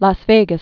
(läs vāgəs)